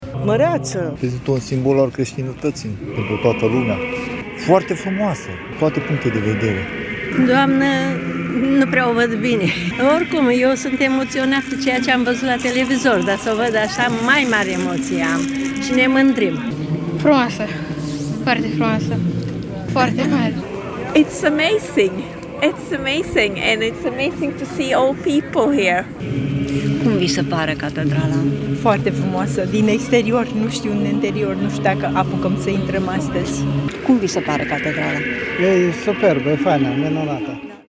Ce spun credincioșii despre Catedrala Națională?